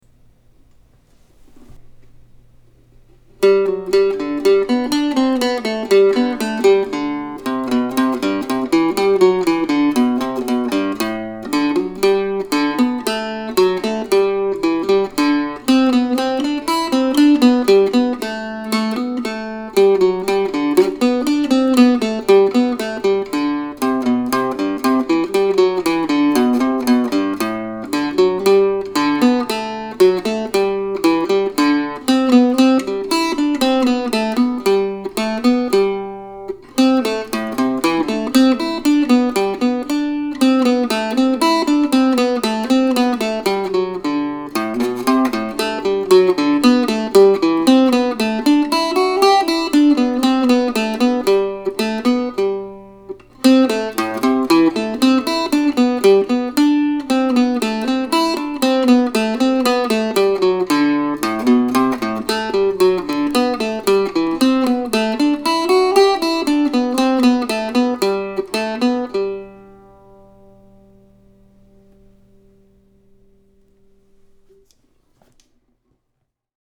Autumn Suite, November, 2020 (for Octave Mandolin or Mandocello)